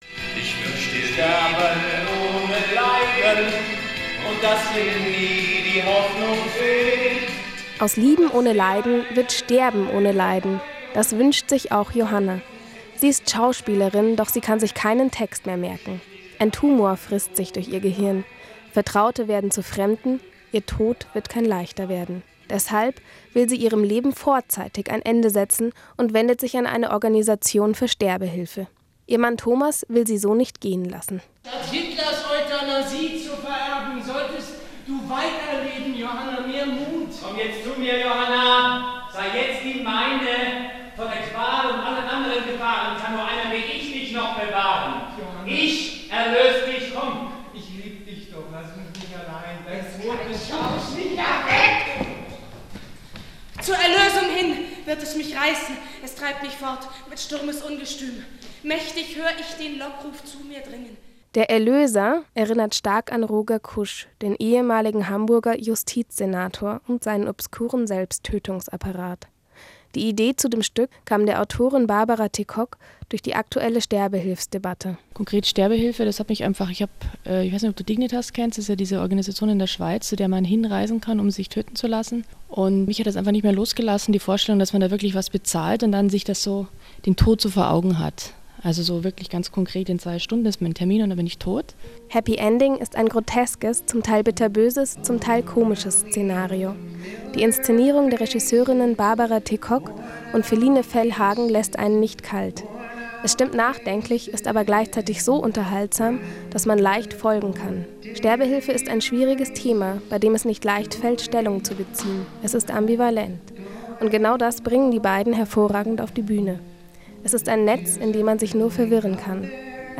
» Radiobeitrag (3:25 Min. / 6,3 MB).